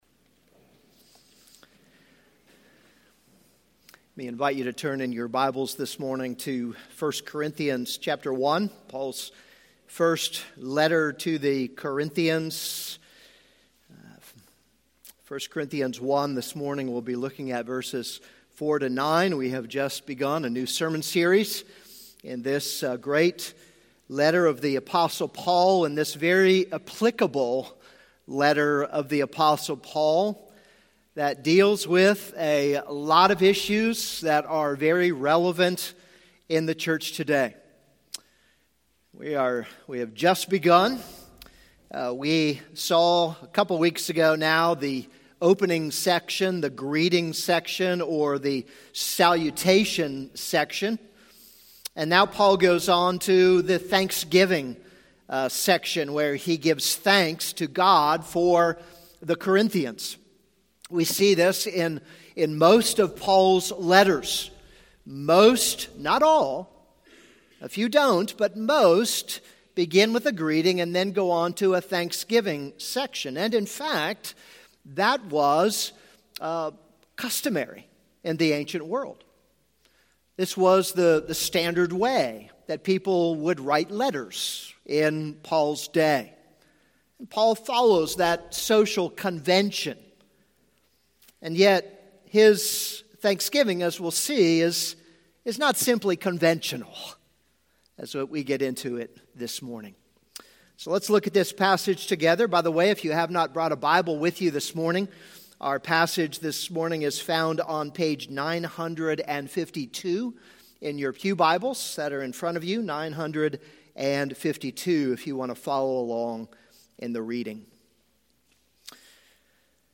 This is a sermon on 1 Corinthians 1:4-9.